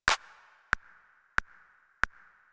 14 Clapping and Counting Basic Rhythms
Figure 14.1 Whole note. [Image description – See Appendix C Figure 14.1]